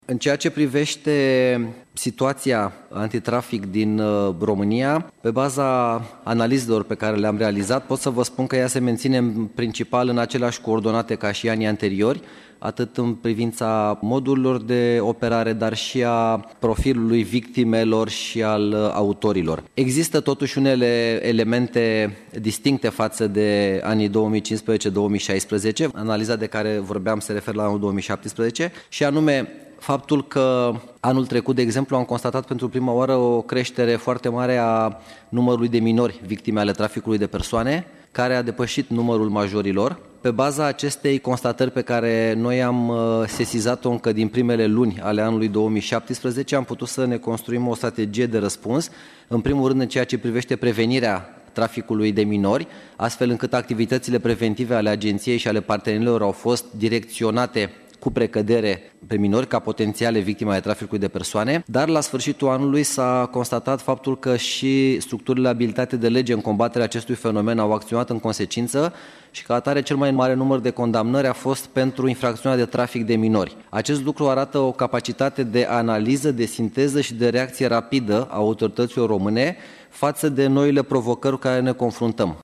Nicolae Maximilian directorul Agenţiei Naţionale Împotriva Traficului de Persoane (ANITP) a declarat pe această temă: